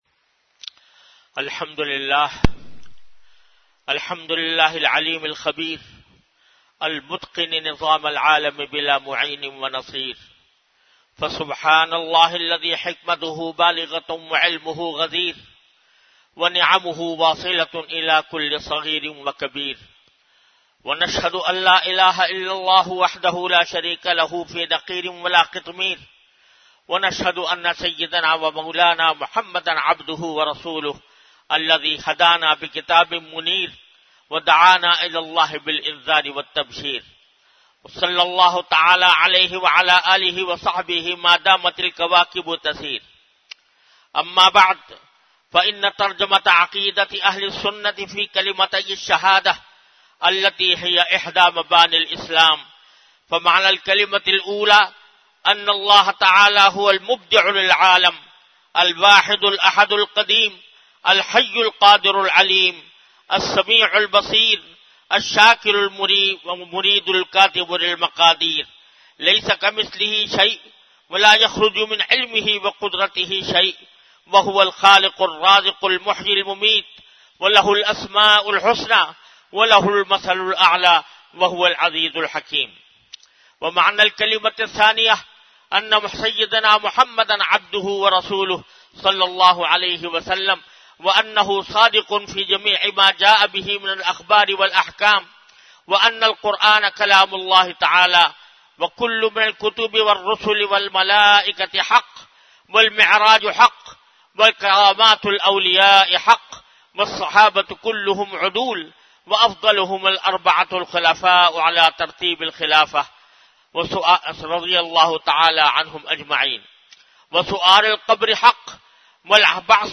Khutba e Juma - Arabic 02-Mar-2018
by Hazrat Mufti Muhammad Taqi Usmani Sahab (Db)
VenueJamia Masjid Bait-ul-Mukkaram, Karachi
Event / TimeBefore Juma Prayer